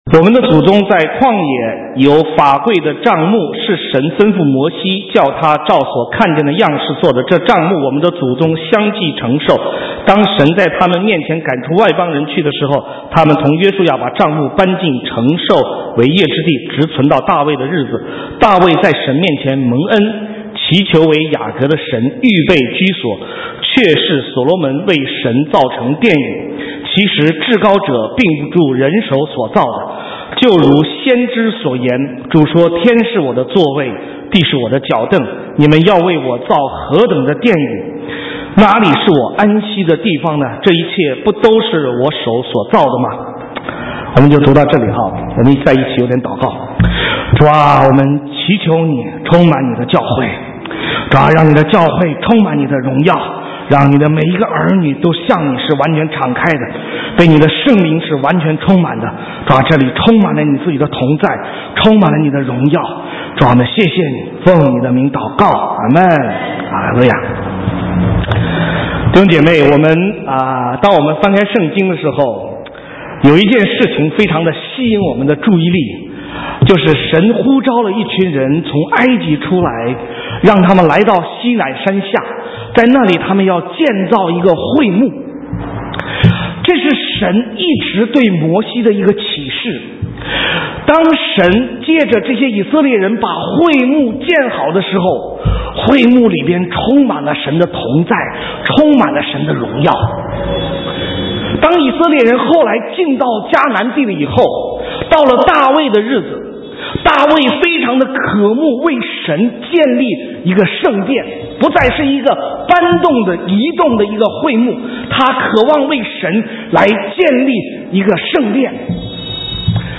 神州宣教--讲道录音 浏览：圣殿的荣耀 (2011-10-02)